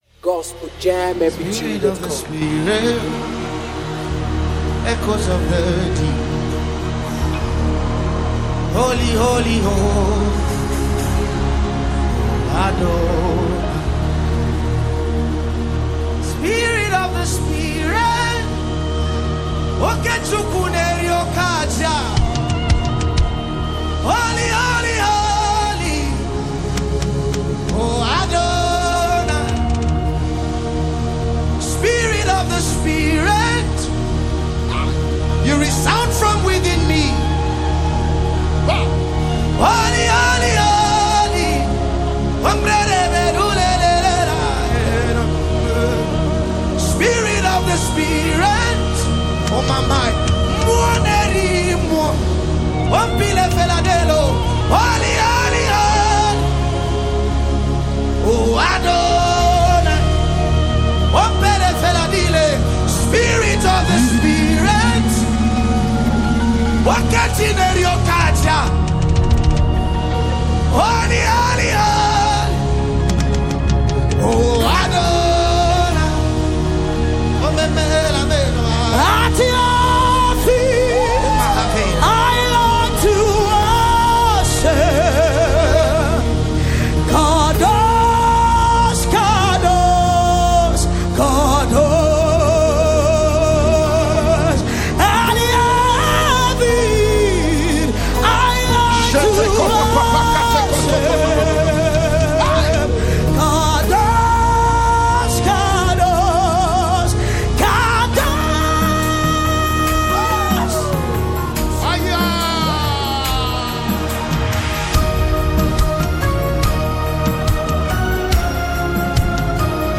a live ministration